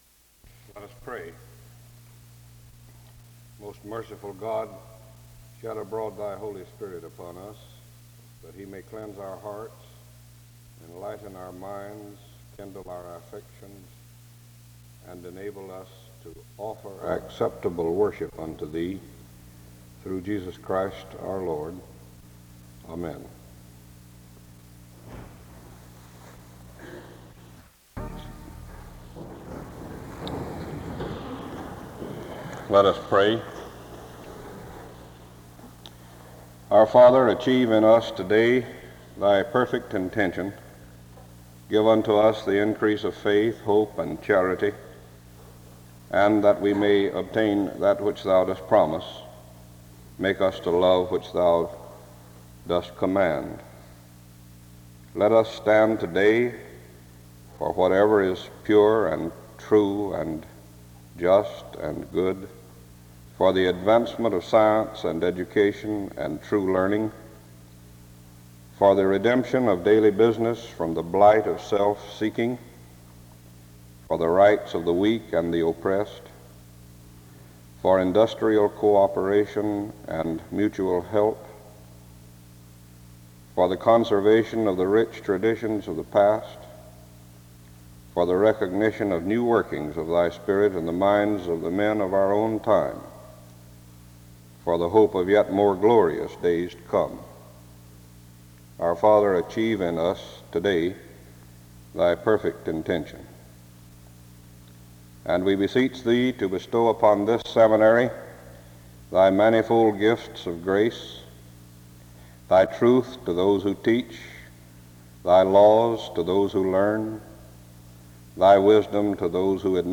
Download .mp3 Description The service opens with prayer, (00:00-02:17) choral singing, (02:18-05:19) and responsive reading, Selection 84 (05:20-07:20).
SEBTS Chapel and Special Event Recordings SEBTS Chapel and Special Event Recordings